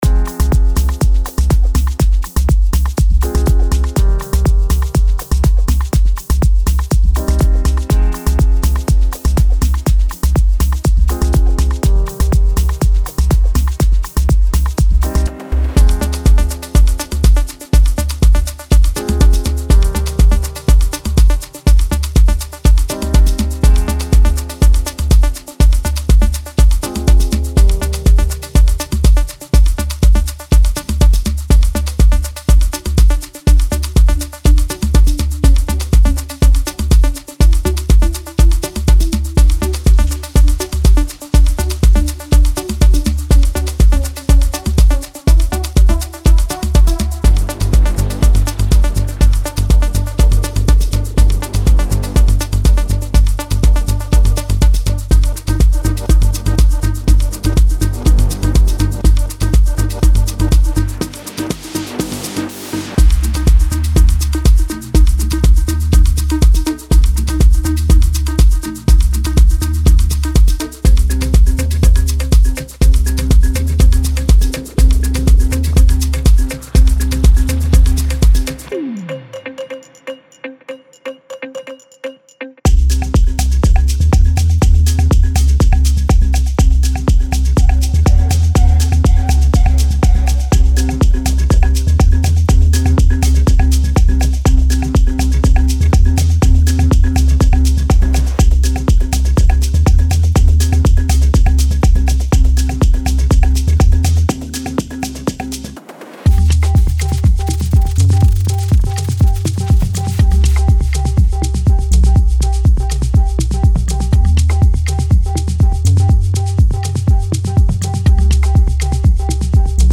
Genre:Afro House
デモサウンドはコチラ↓